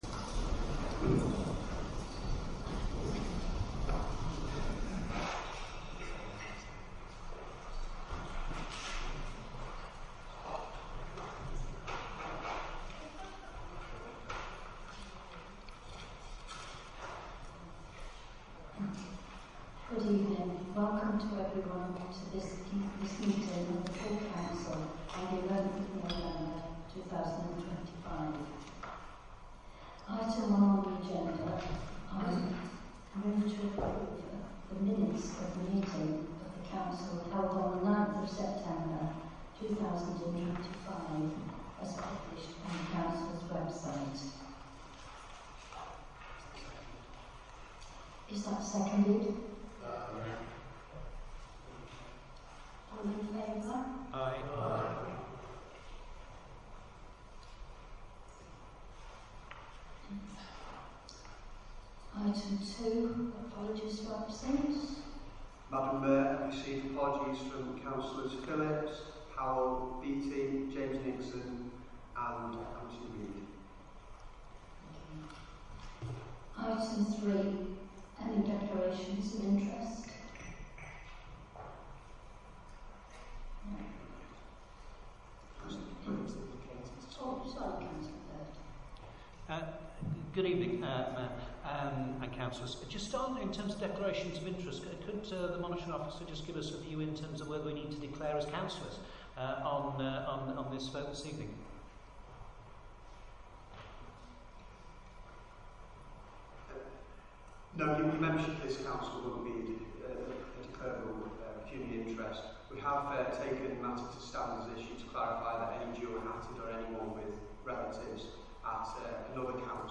Location: Council Chamber, County Buildings, Martin Street, Stafford